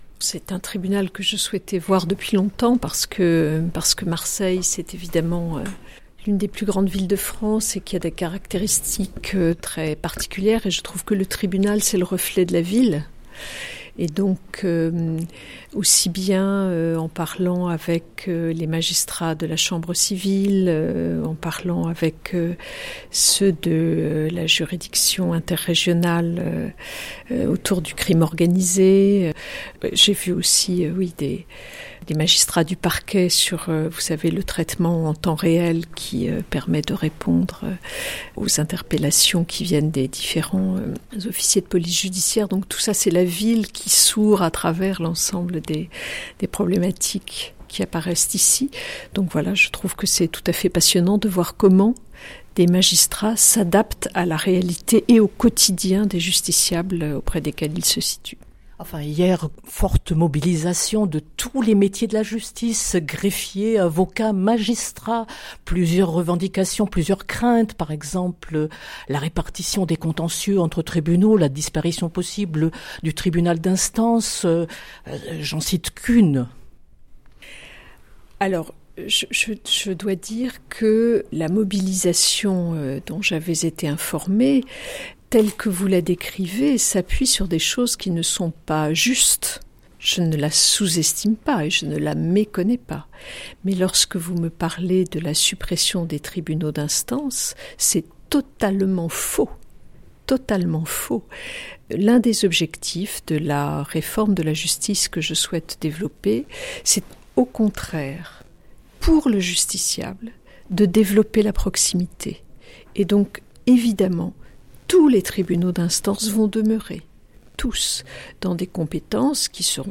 Entretien avec la ministre de la Justice, Nicole Belloubet, en visite à Marseille
La ministre de la Justice, Nicole Belloubet, s’est rendue, ce vendredi au tribunal de Grande Instance de Marseille.
Entretien.